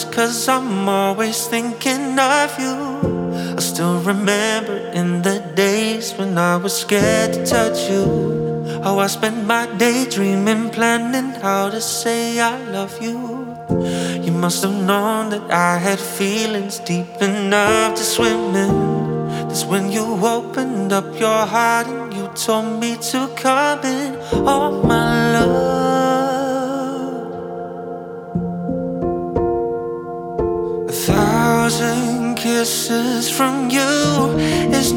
Singer Songwriter Pop
Жанр: Поп музыка